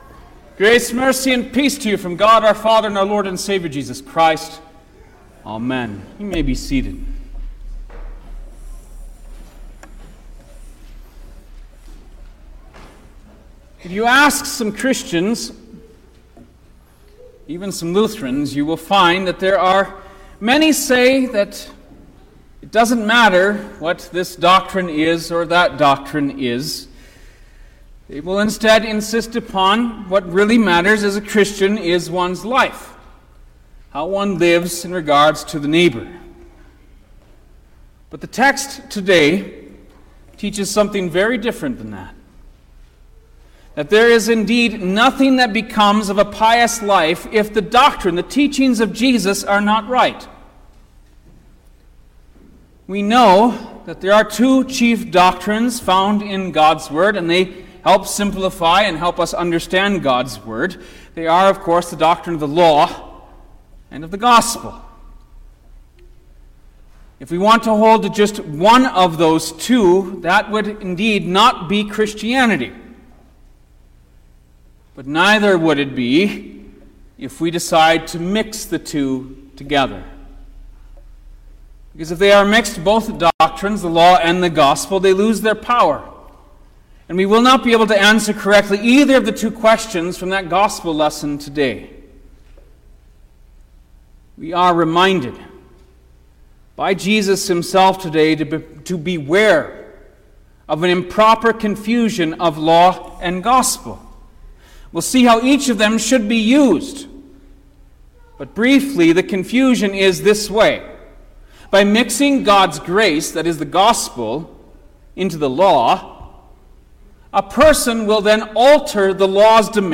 October-8_2023_Eighteenth-Sunday-after-Trinity_Sermon-Stereo.mp3